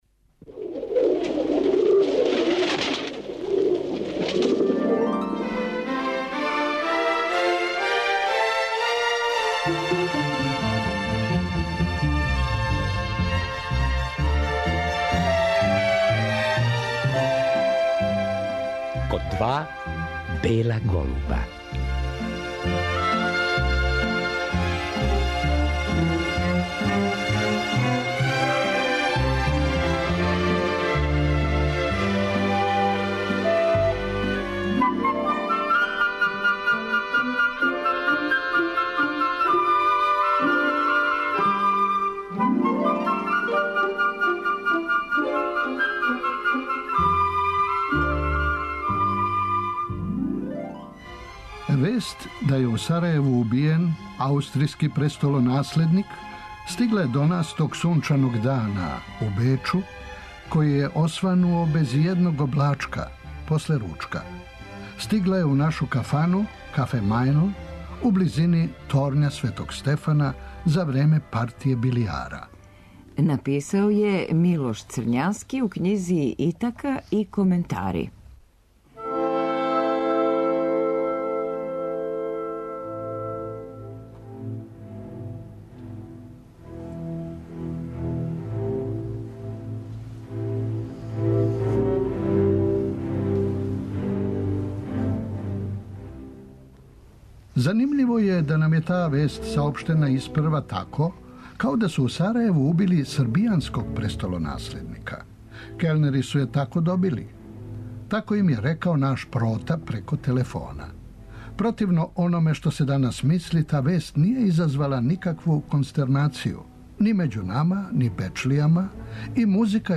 У Тонском архиву Радио Београда сачувана су сећања на суђење Принципу.